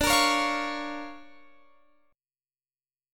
DmM7#5 Chord